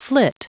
Prononciation du mot flit en anglais (fichier audio)
Prononciation du mot : flit